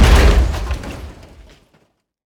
car-stone-impact-3.ogg